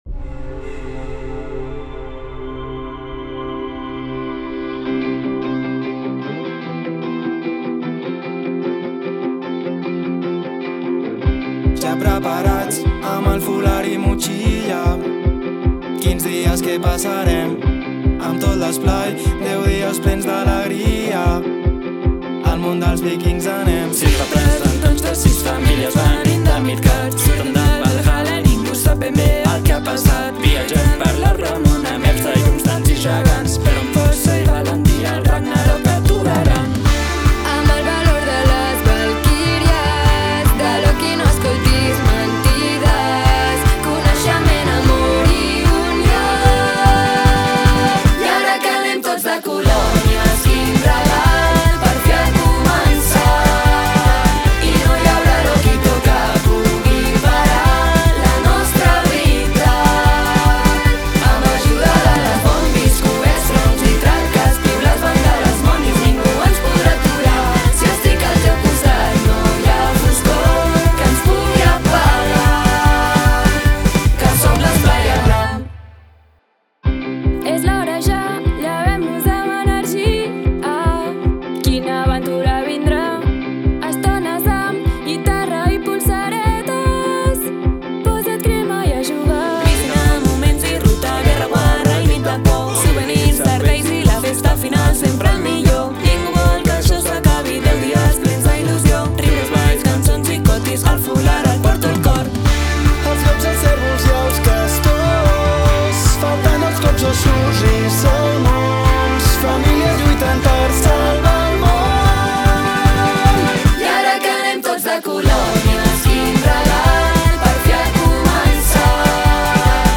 Can Joval 2025